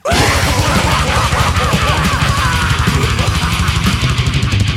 Laugh Deicide Sound Button - Free Download & Play